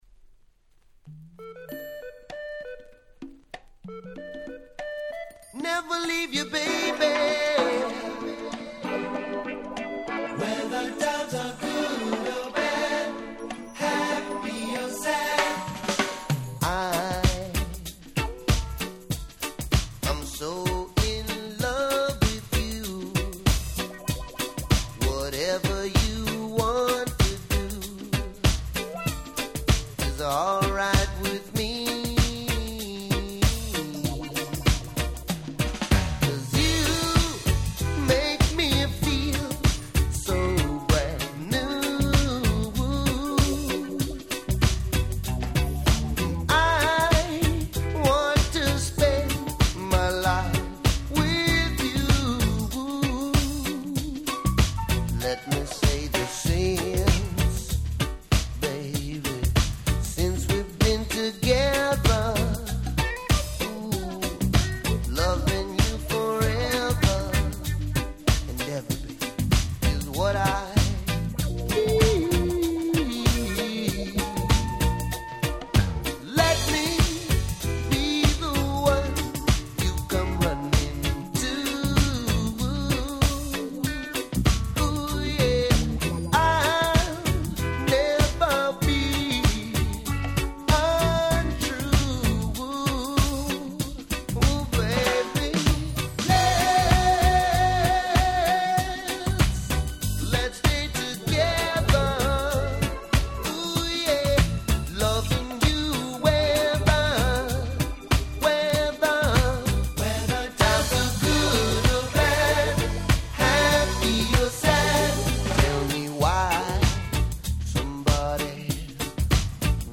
Nice Cover Reggae !!
ラバーズレゲエ